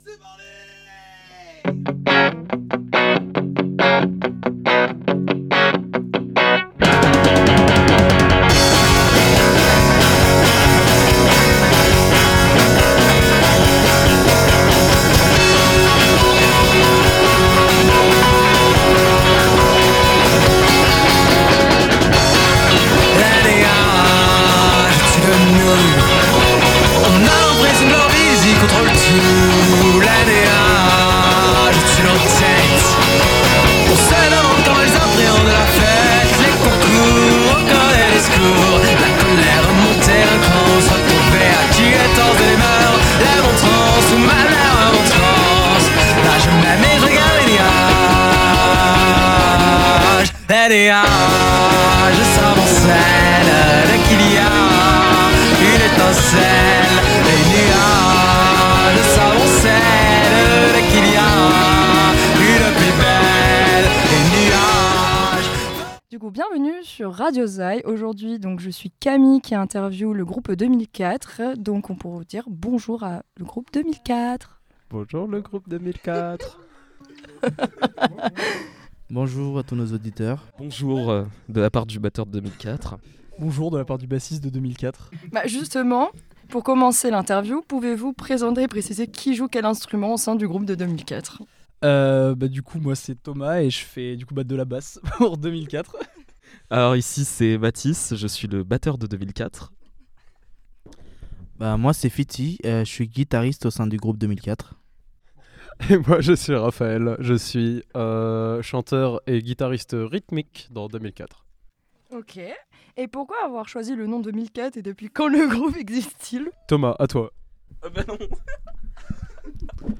Interview avec 2004